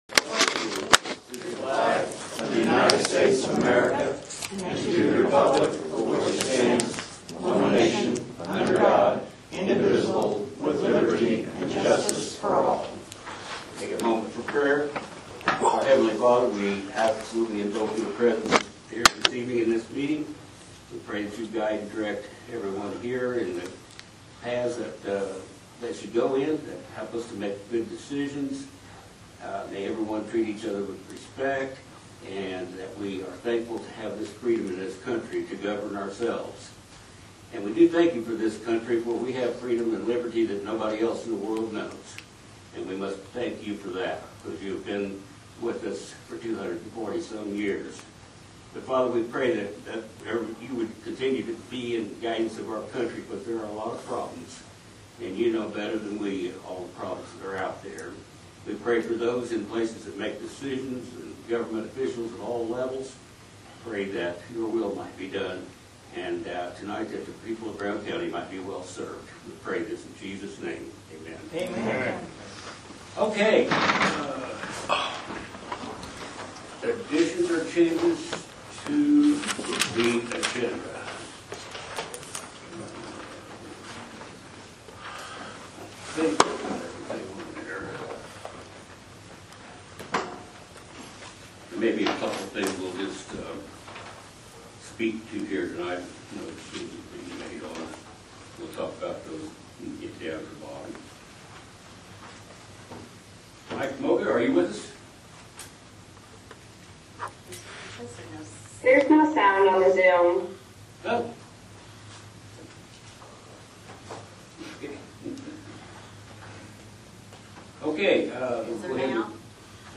Commissioner Meeting Notes – July 19, 2023, 6-8 pm.“Interesting” meeting and very contentious at times.